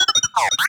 sci-fi_driod_robot_emote_beeps_07.wav